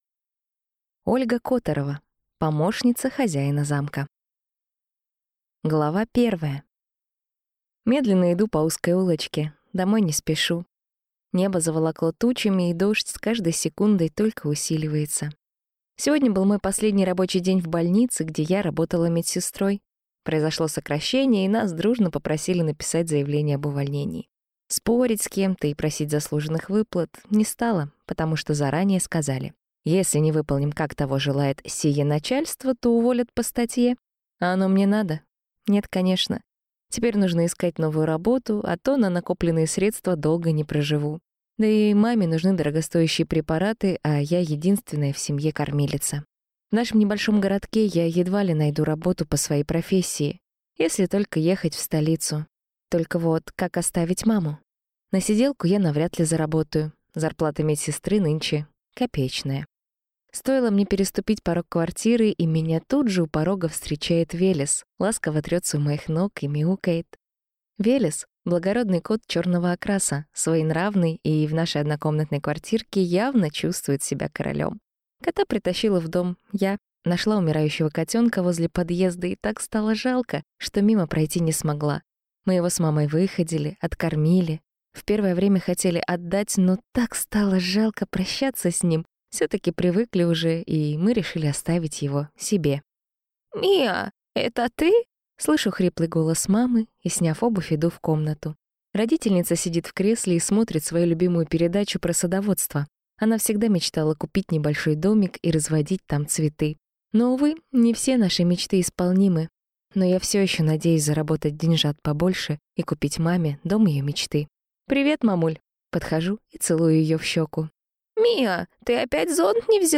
Аудиокнига Помощница хозяина замка | Библиотека аудиокниг
Прослушать и бесплатно скачать фрагмент аудиокниги